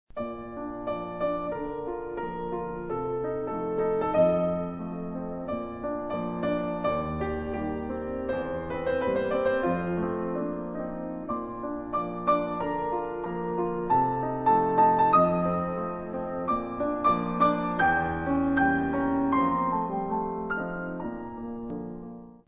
violino
pianoforte